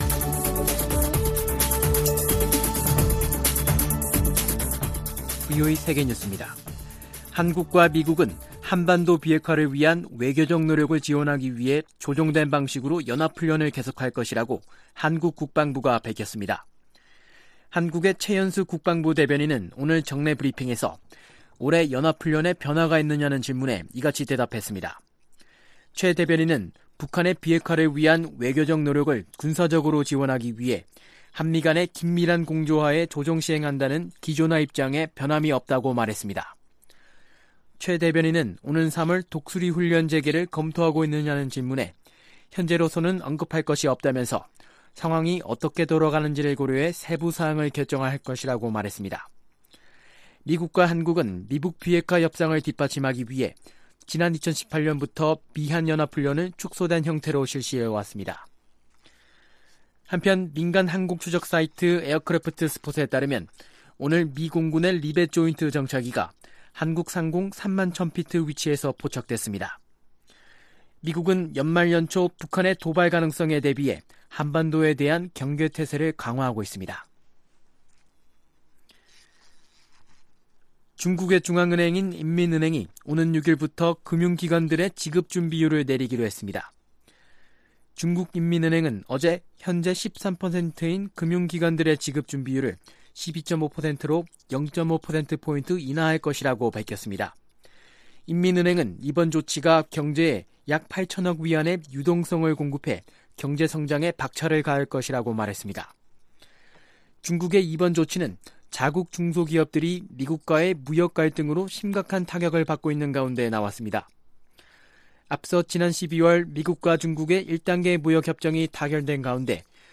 VOA 한국어 간판 뉴스 프로그램 '뉴스 투데이', 2019년 1월 2일 2부 방송입니다. 김정은 북한 국무위원장은 당 전원회의 보고를 통해 미국의 기존 입장에 변화가 없는데 대한 불만을 나타냈다고 미국의 전직 관리들이 분석했습니다. 한국 통일연구원은 북한의 당 중앙위원회 전원회의 분석을 통해 1~2월이 한반도 정세의 중대 고비가 될 것이라고 전망했습니다.